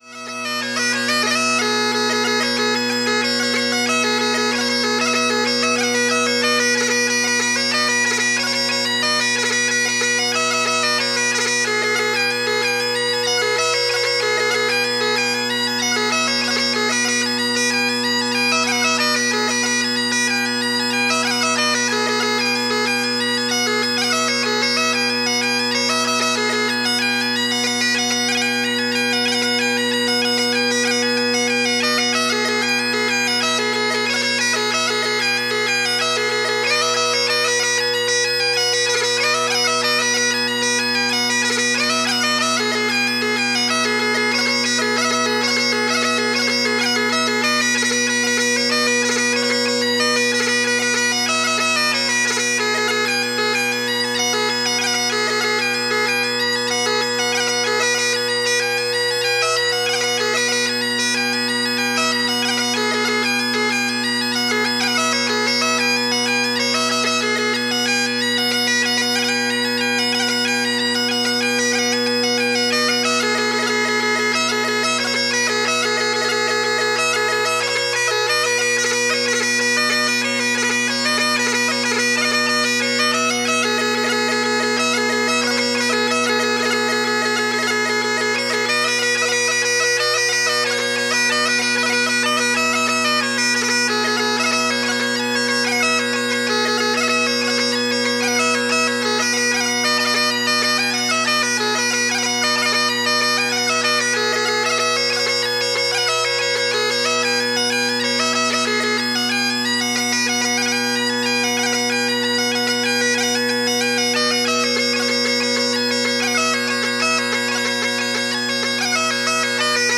The album was recorded in Houffalize, Belgium.
The first would be a set of hornpipes.
But it’s great fun to play, great rhythmic effect.